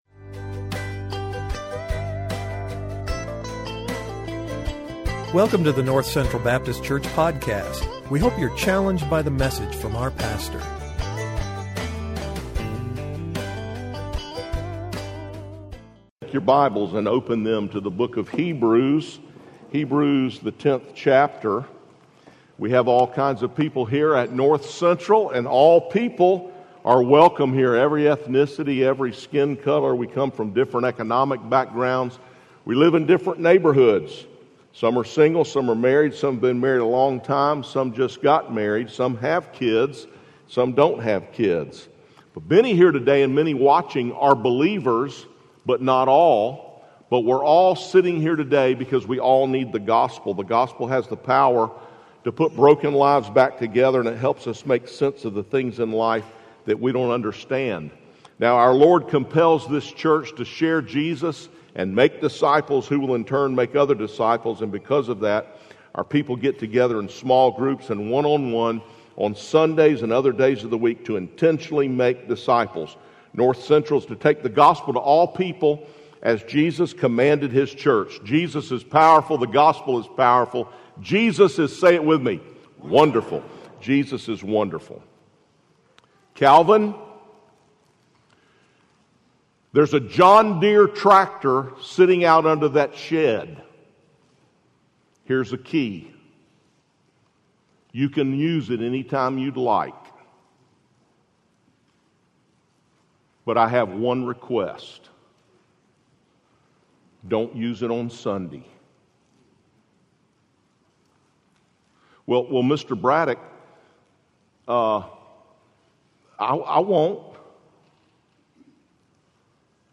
Hebrews Watch Listen Save Welcome to our online service!